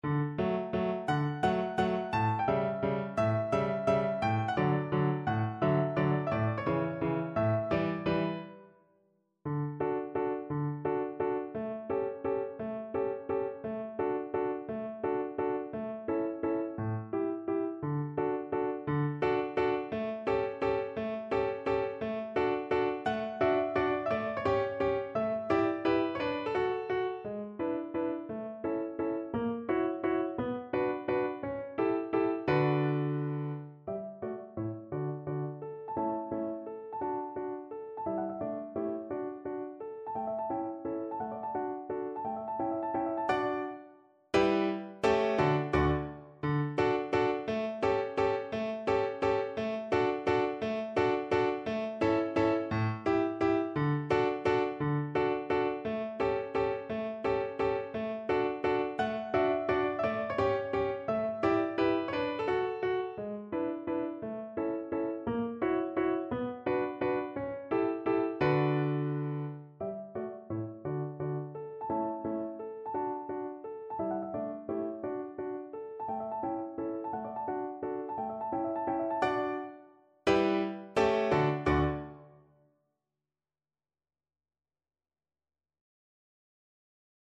Verdi: La donna è mobile (na flet i fortepian)
Symulacja akompaniamentu